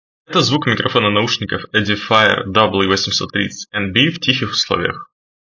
Edifier W830NB — Микрофон:
Не ужасно, однако в шумных условиях микрофон может отрезать половину слова.
В тихих условиях: